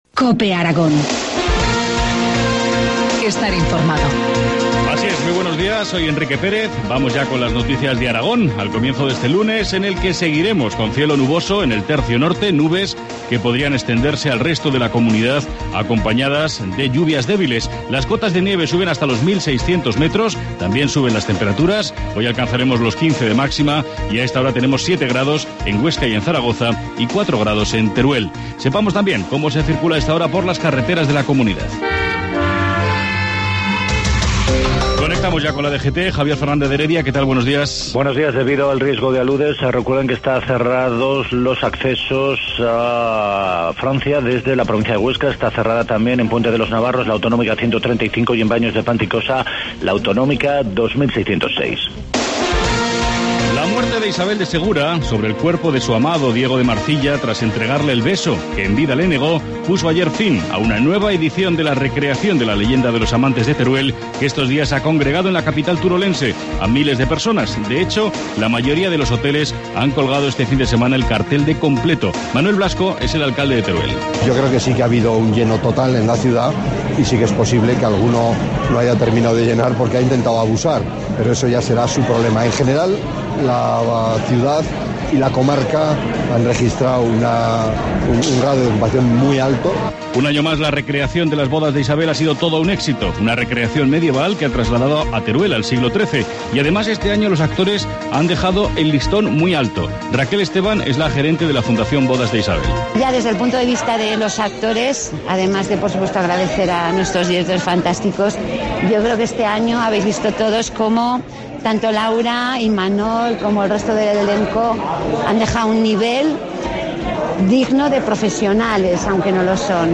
Informativo matinal, lunes 18 de febrero, 7.25 horas